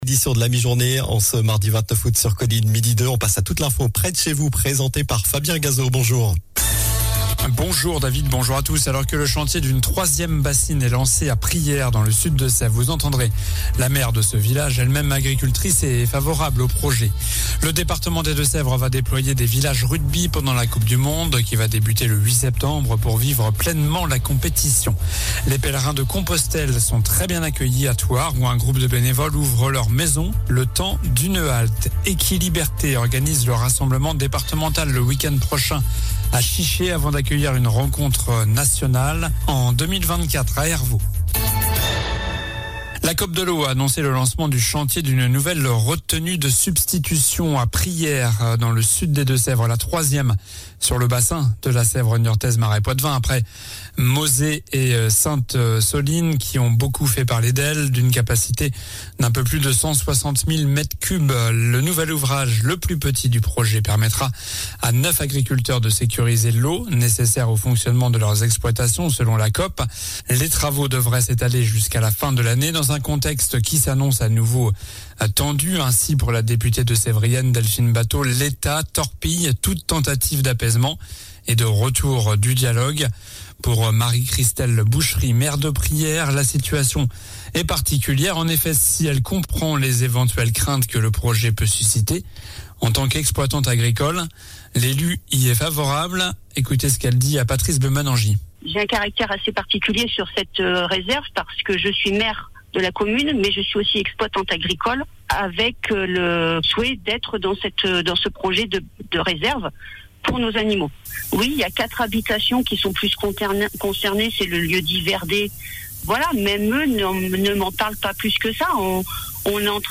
Alors que le chantier d'une 3e bassine est lancé à Priaires, vous entendrez la maire de ce village, elle-même agricultrice et favorable au projet. - Le Département va déployer des villages rugby pendant la Coupe du monde - Les pélerins de Compostelle sont très bien accueillis à Thouars - Equiliberté organise le rassemblement départemental le WE prochain à Chiché (photo) 0:00 11 min 58 sec